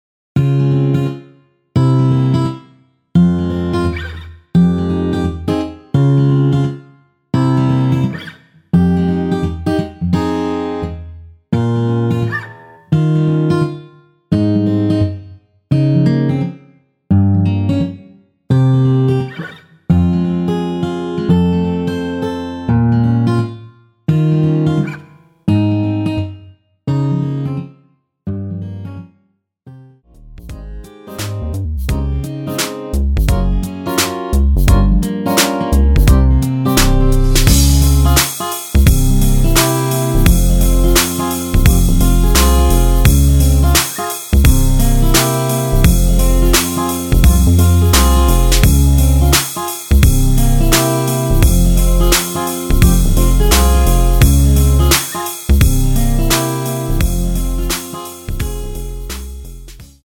MR 입니다.
Fm
◈ 곡명 옆 (-1)은 반음 내림, (+1)은 반음 올림 입니다.
앞부분30초, 뒷부분30초씩 편집해서 올려 드리고 있습니다.